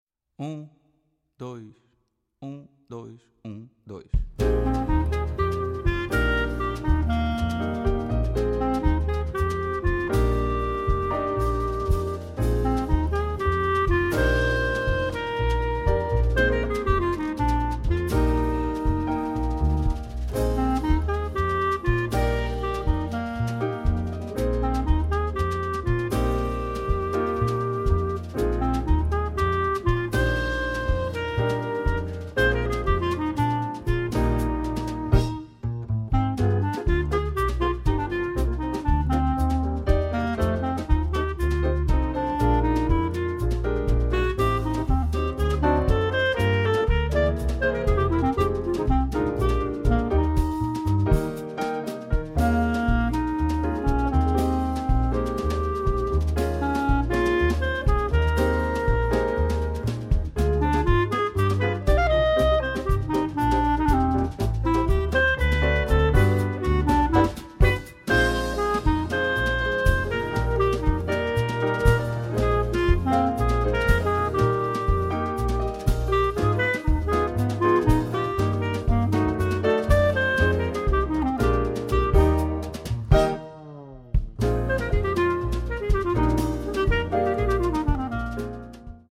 Voicing: Clarinet w/ Audio